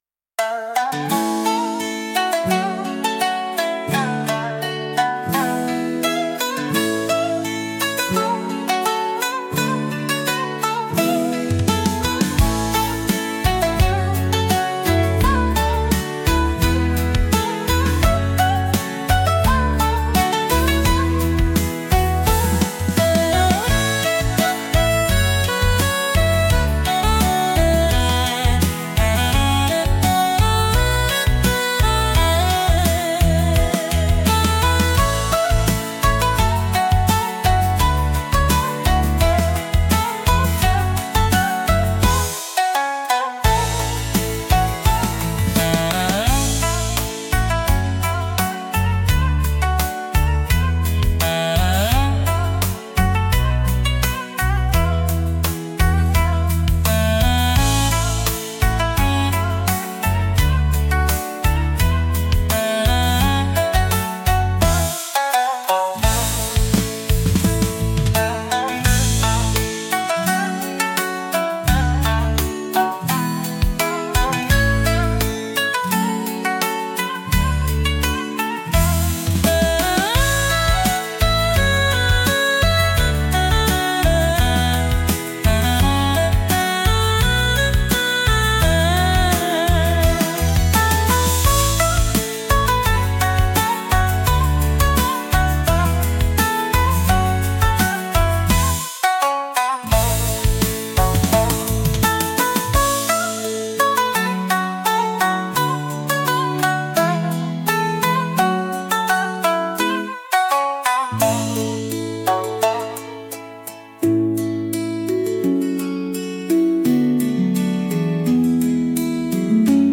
和の心を忘れないためのBGM